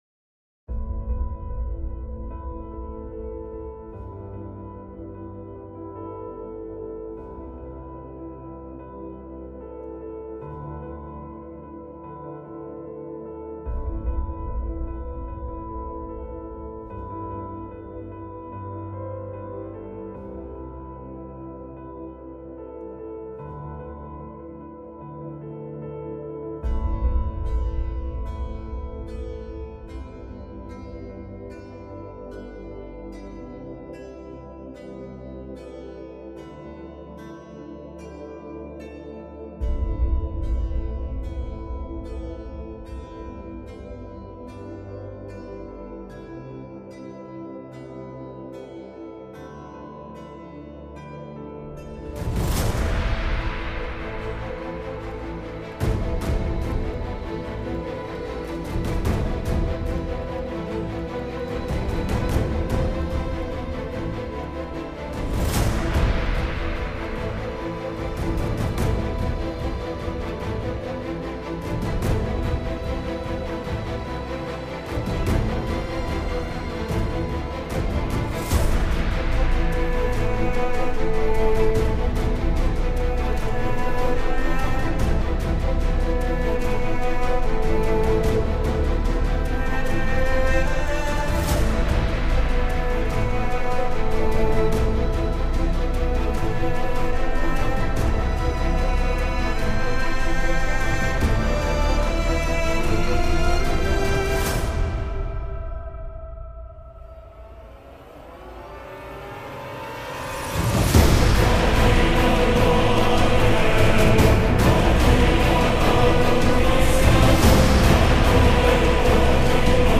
موسیقی بیکلام موسیقی حماسی موسیقی فاخر